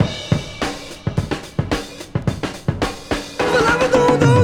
• 108 Bpm Drum Groove F# Key.wav
Free drum beat - kick tuned to the F# note. Loudest frequency: 819Hz
108-bpm-drum-groove-f-sharp-key-8Lw.wav